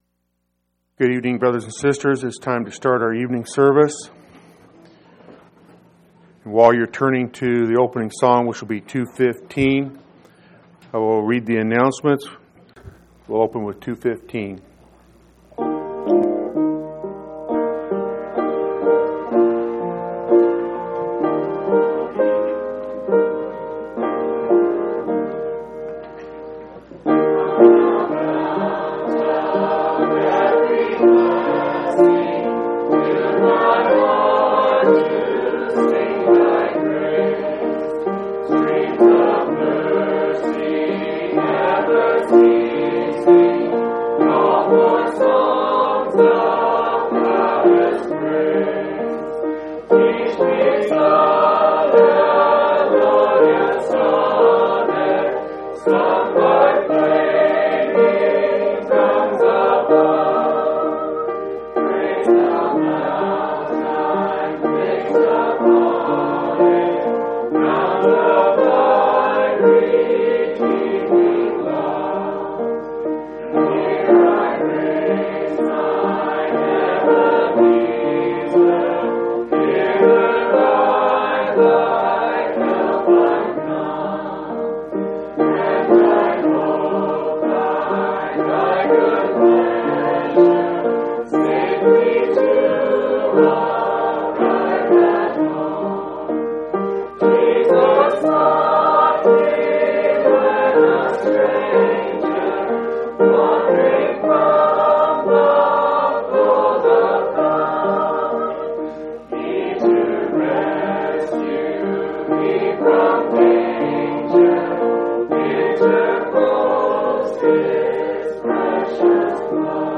8/31/2003 Location: Phoenix Local Event